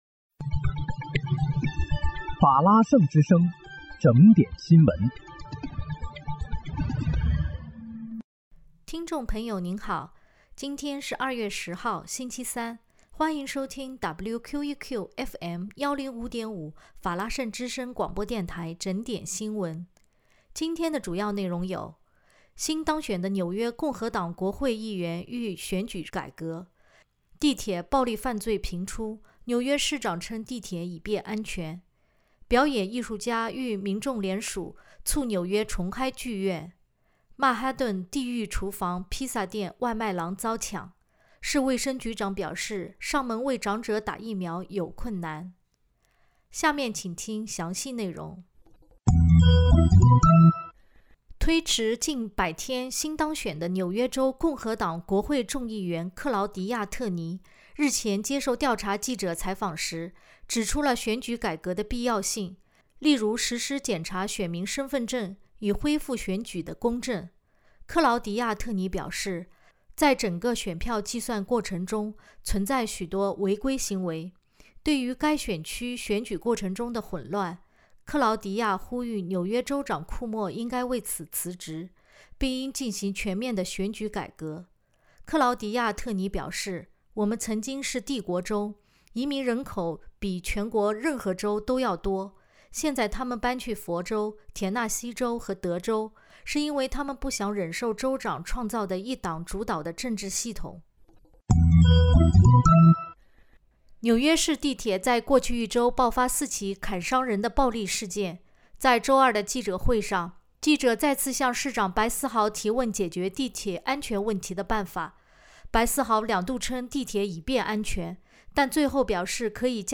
2月10日（星期三）纽约整点新闻